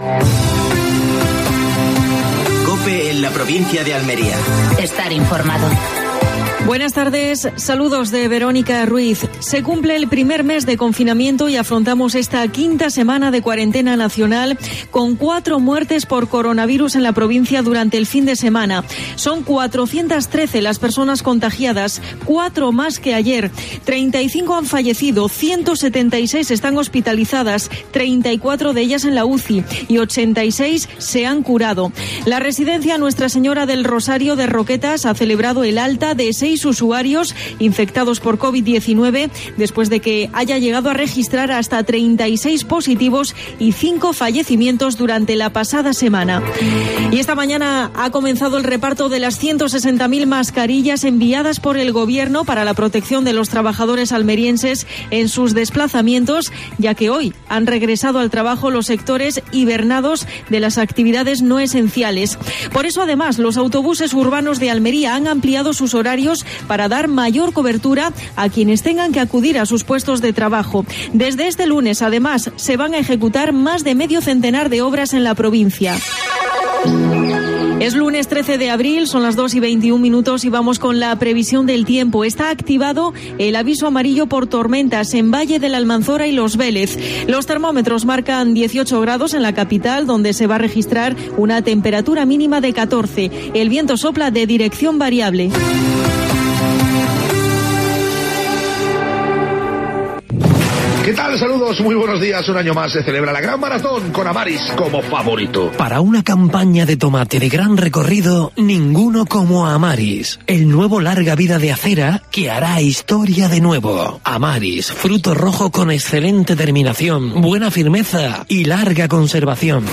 AUDIO: Última hora en Almería. Datos actualizados por el coronavirus en la provincia. Entrevista a Ramón Fernández-Pacheco (alcalde de Almería).